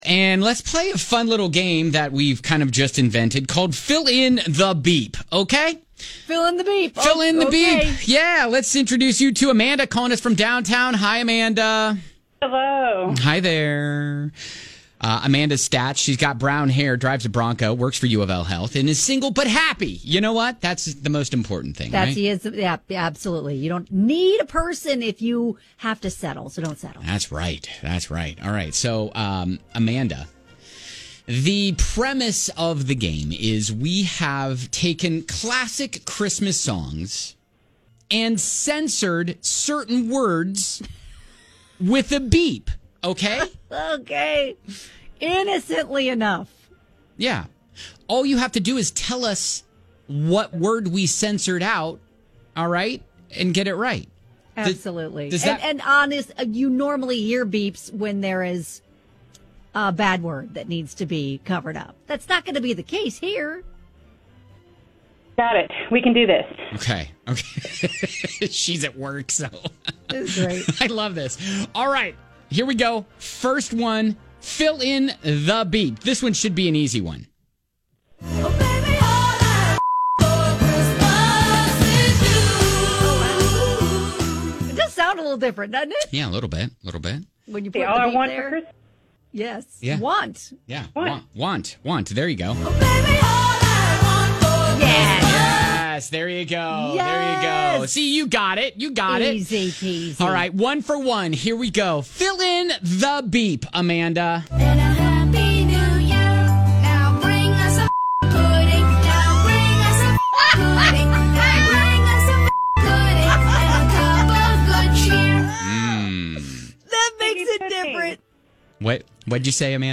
We’ve taken classic Christmas songs and censored certain words with a beep - just fill in the BEEP and win!